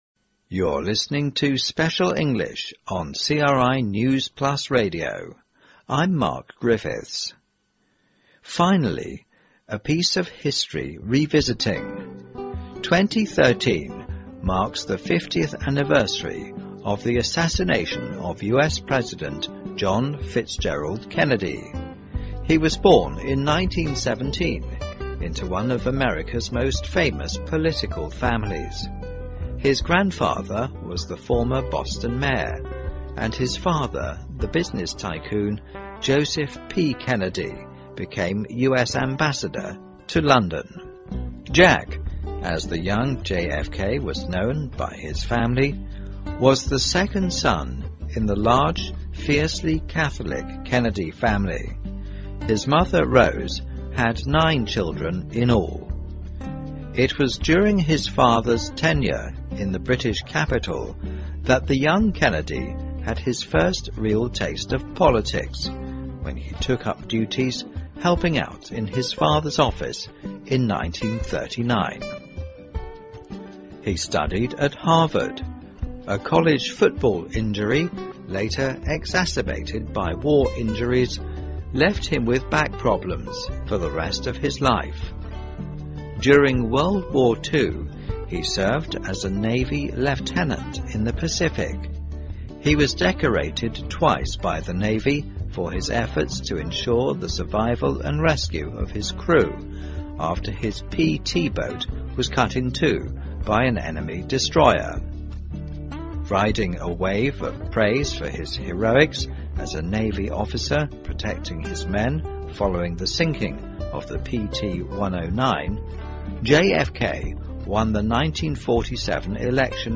News Plus慢速英语:肯尼迪遇刺50周年:还原魅力总统的真实人生